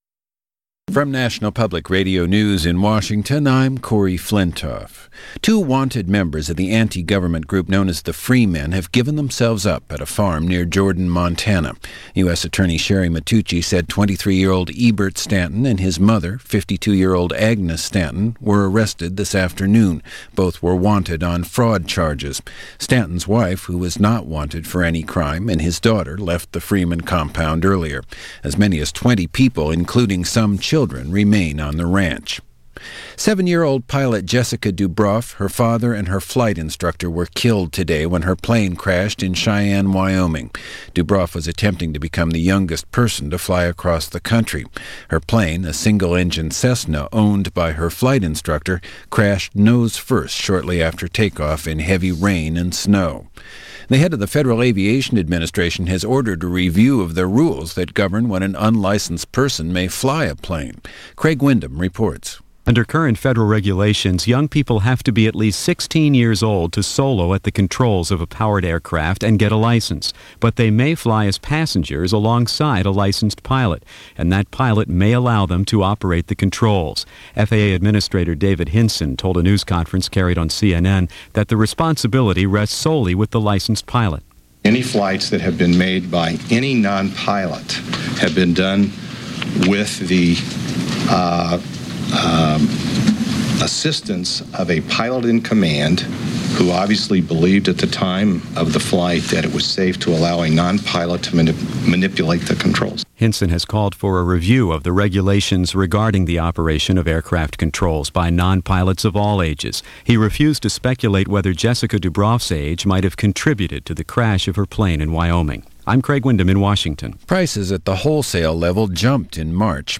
And that’s a small chunk of what went on, this April 11th in 1996 as reported by National Public Radio from All Things Considered.